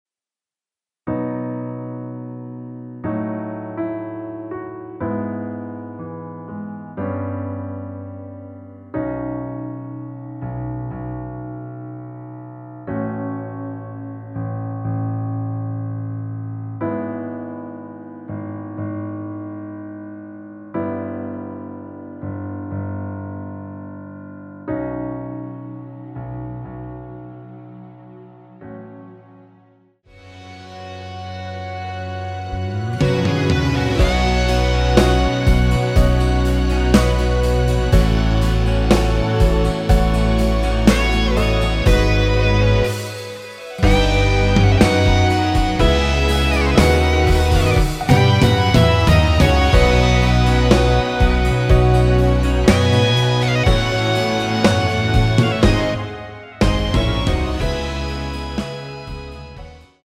대부분의 남성분이 부르실수 있는키로 제작 하였습니다.
앞부분30초, 뒷부분30초씩 편집해서 올려 드리고 있습니다.
중간에 음이 끈어지고 다시 나오는 이유는